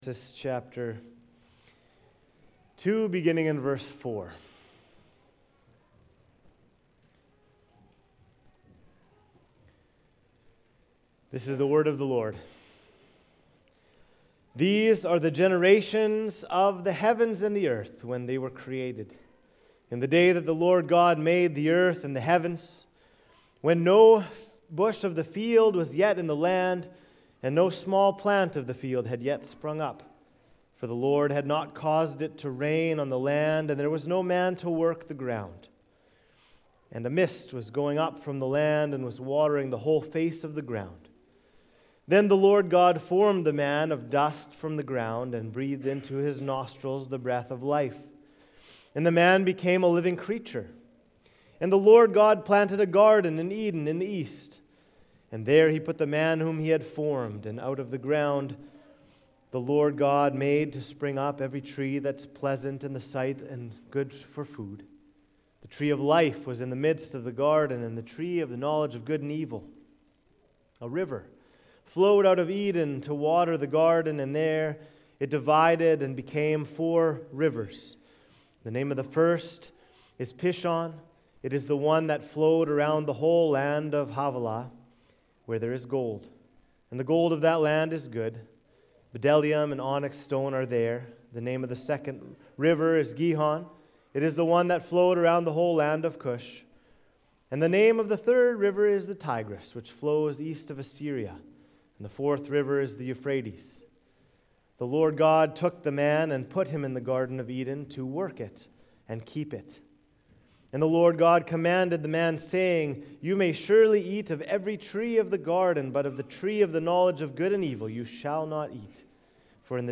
Sermons | Cornerstone Bible Church